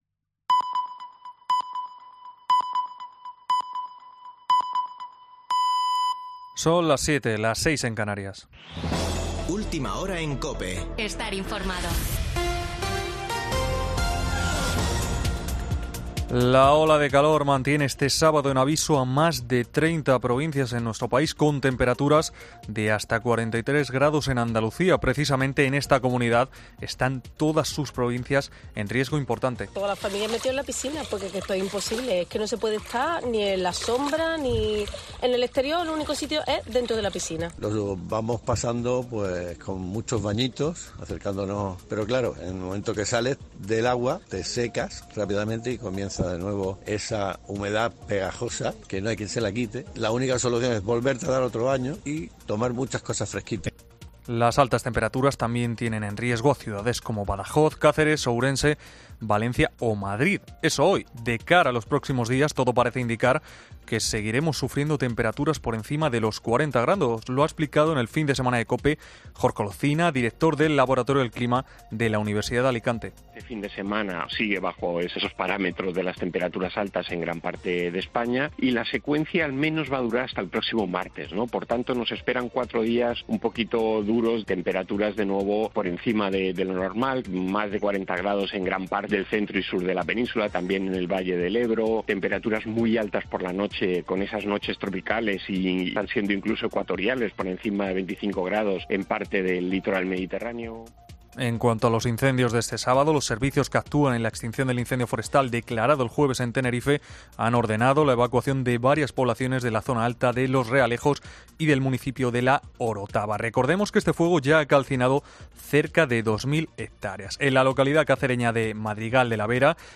Boletín de noticias de COPE del 23 de julio de 2022 a las 19:00 horas